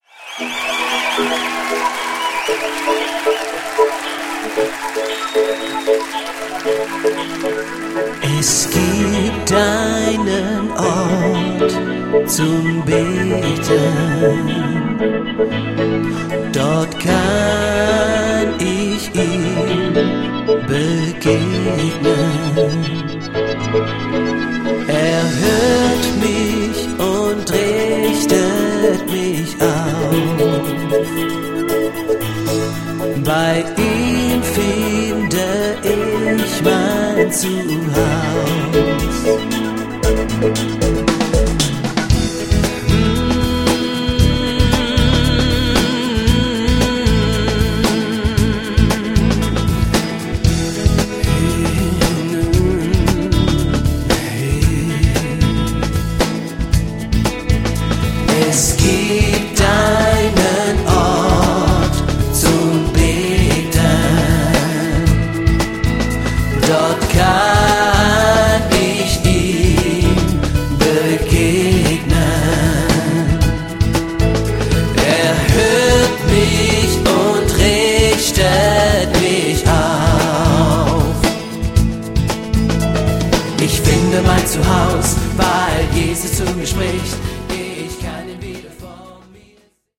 • Sachgebiet: deutscher Lobpreis